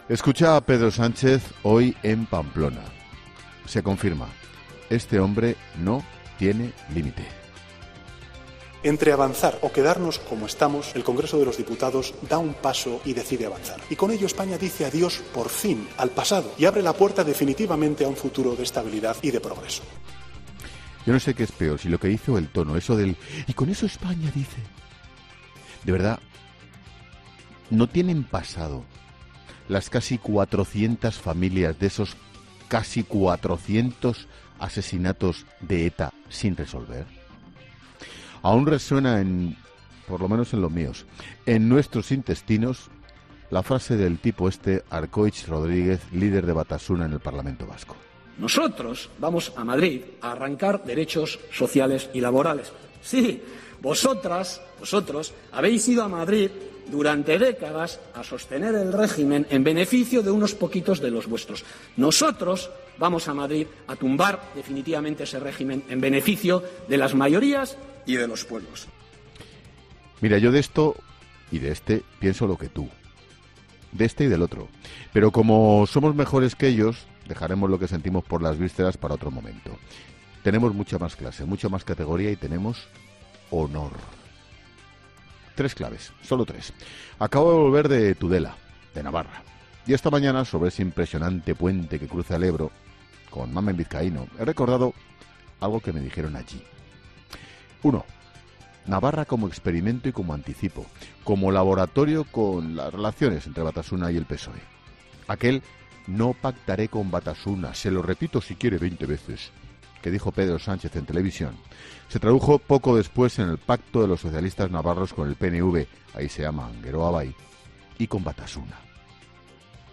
Monólogo de Expósito